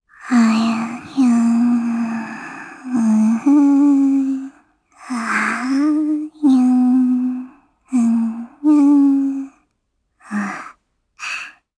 Gremory-Vox_Hum_jp_b.wav